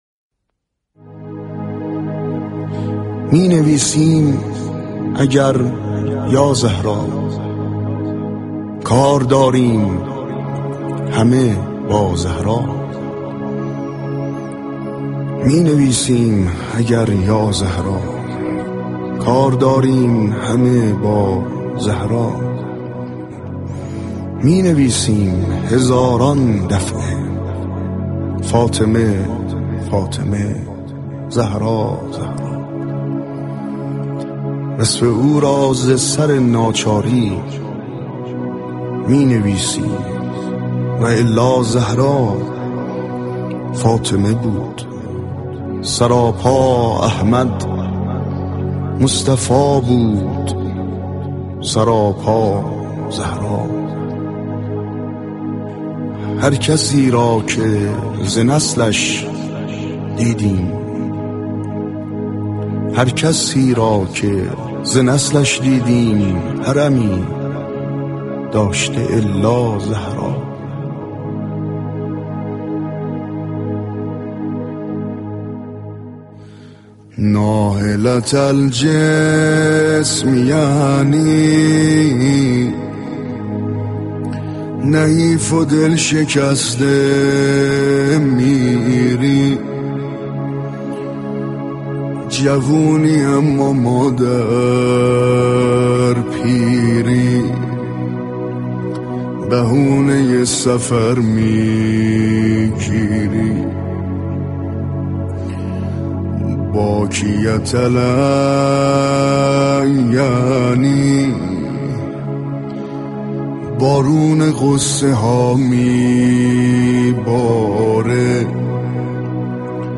- بهره گیری از كارشناس و آوا و نوای مذهبی